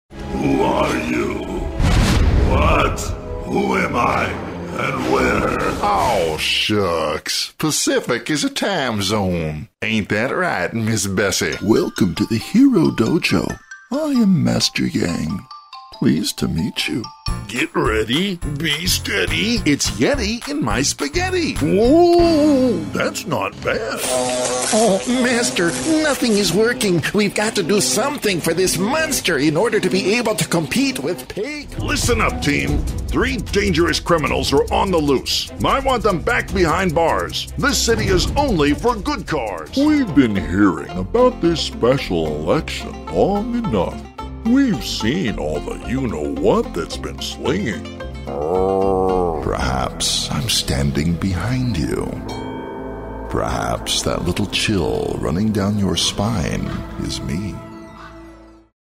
Resonant, deep baritone voice with a warm delivery.
Mid-western, west coast American English